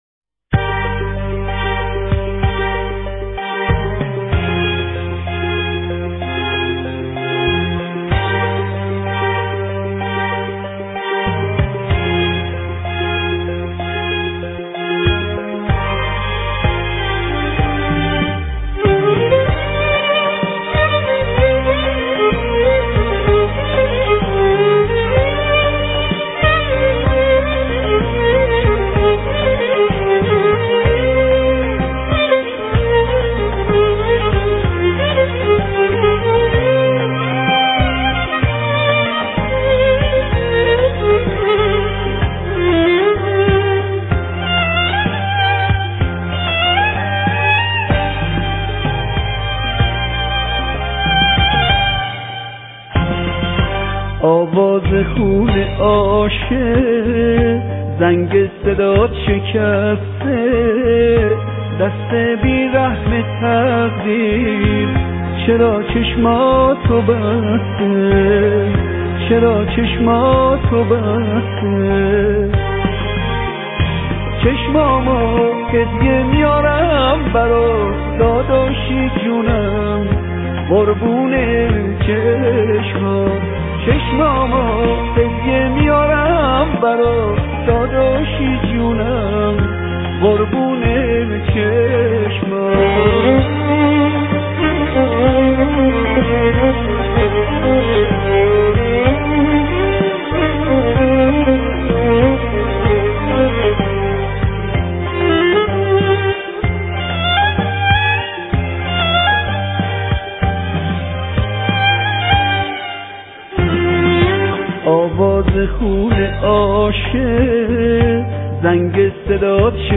آهنگ قدیمی آهنگ غمگین